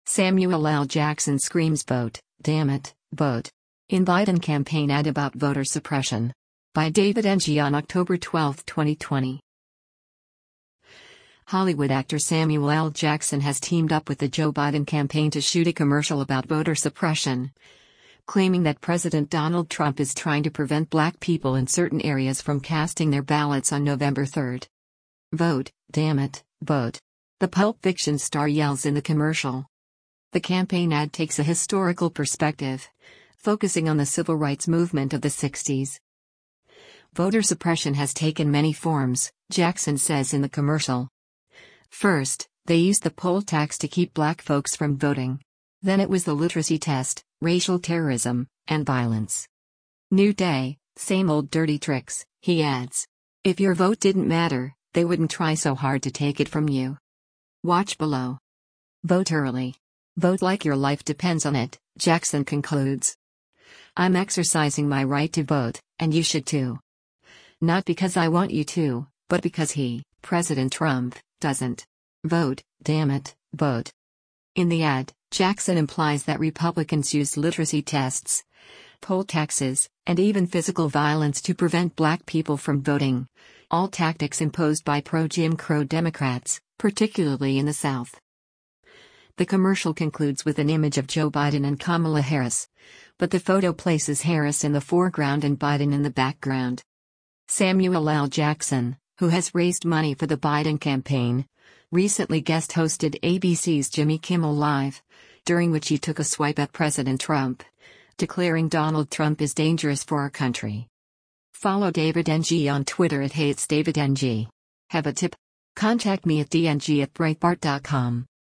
“Vote, dammit, vote!” the Pulp Fiction star yells in the commercial.